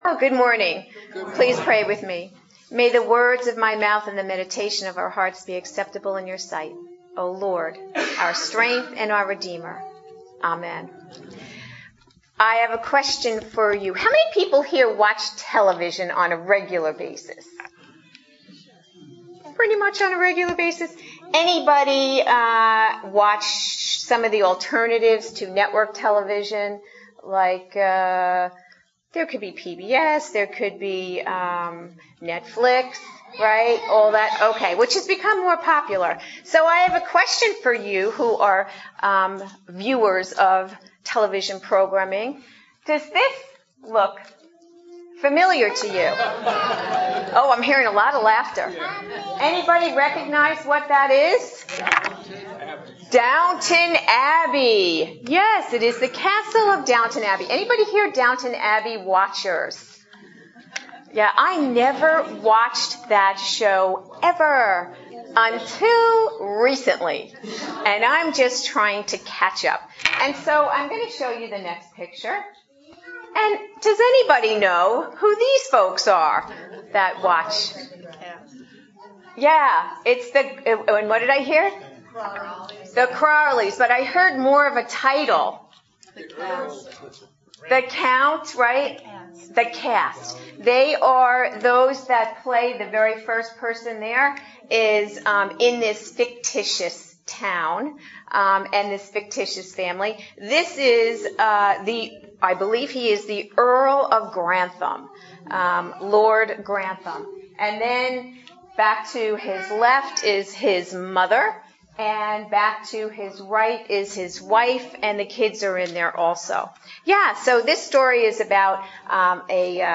Series: Adult Sermons